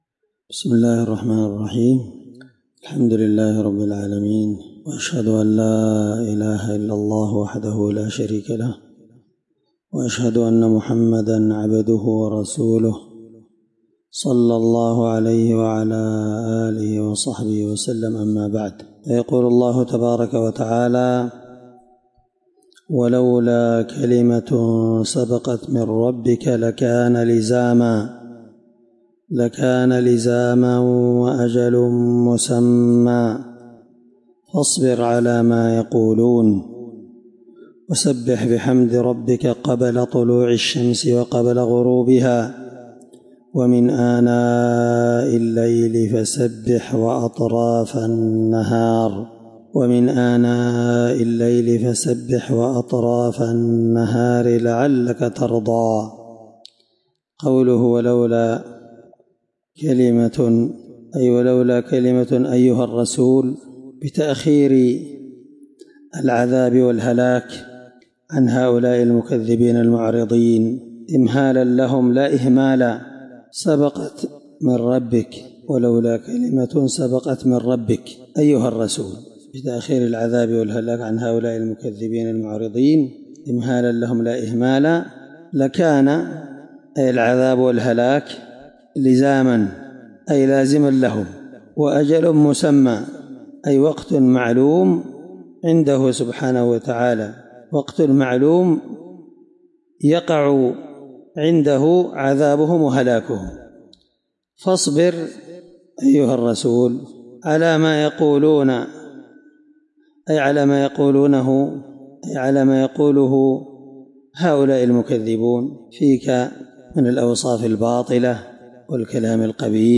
الدرس25تفسير آية (129-130) من سورة طه